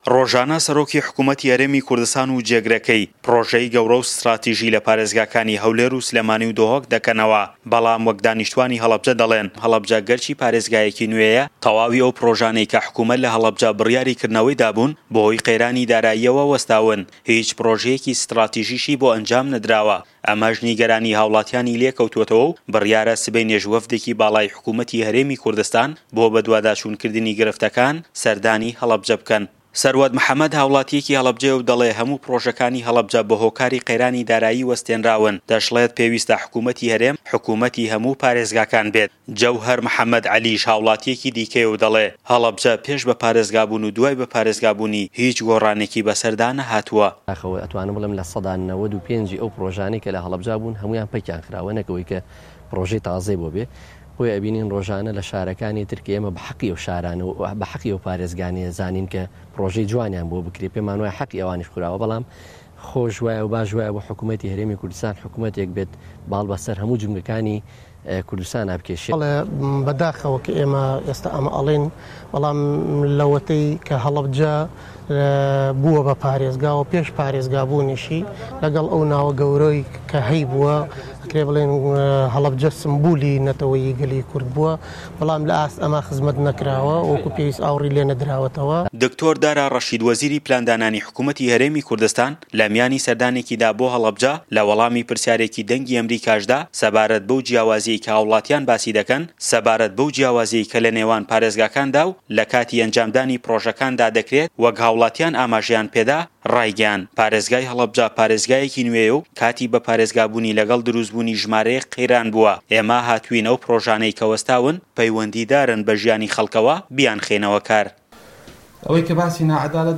ڕاپـۆرتی